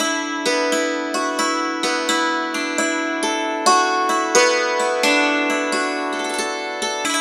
Dulcimer16_133_G.wav